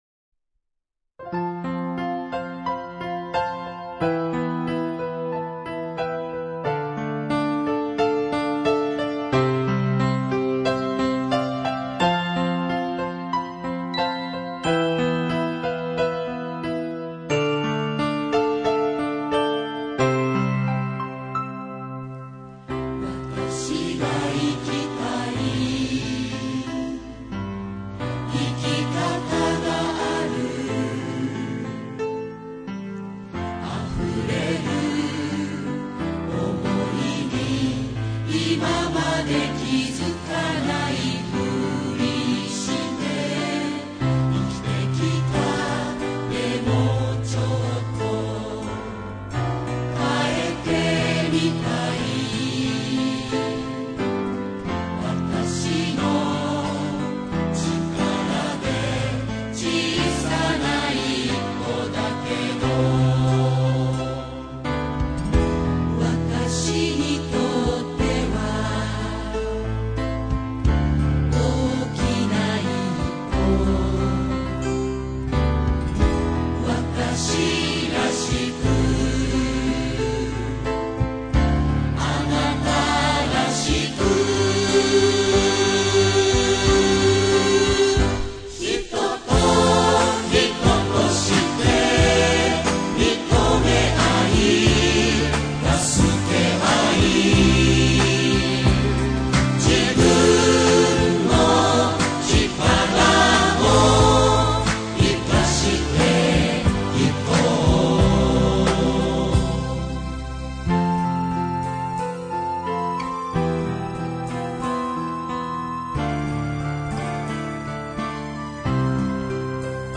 主旋律とアルト、テナーの副旋律の3部で構成しています。